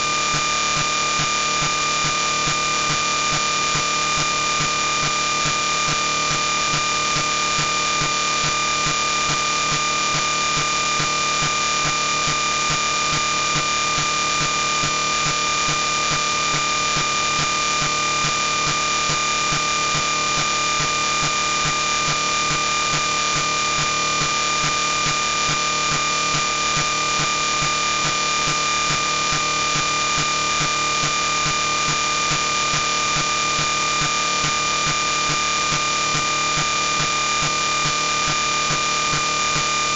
CDMA420-sound.mp3